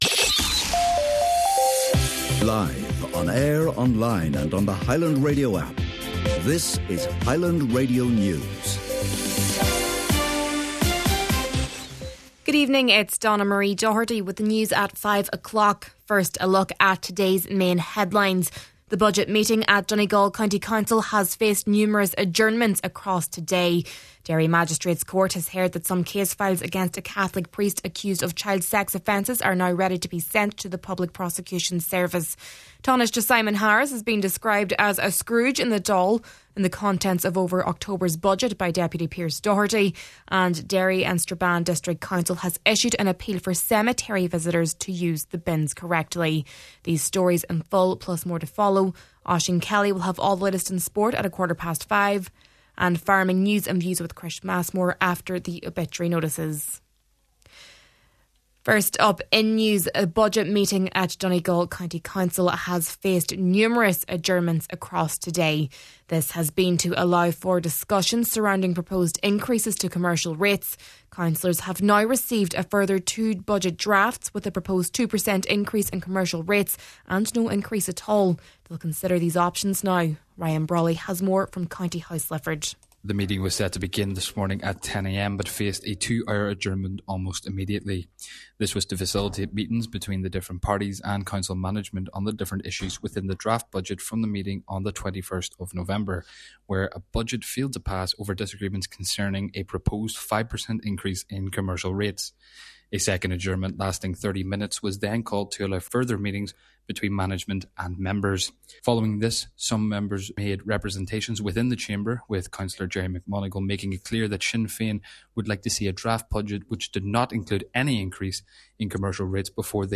Main Evening News, Sport, Farming News and Obituary Notices – Thursday, December 4th